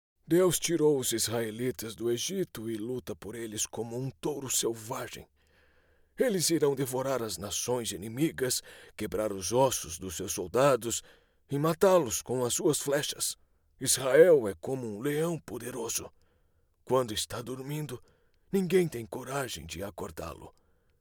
Com uma gama de graves e contralto e configuração de estúdio profissional, ele oferece serviços de locução amigáveis e especializados para marcas que buscam clareza e autenticidade.
Focusrite Scarlet Solo + microfone Akg c3000
GravesContralto
DinâmicoNeutroAmigáveisConversacionalCorporativoVersátil